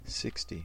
Ääntäminen
Ääntäminen US Tuntematon aksentti: IPA : /ˈsɪks.ti/ Haettu sana löytyi näillä lähdekielillä: englanti Käännöksiä ei löytynyt valitulle kohdekielelle.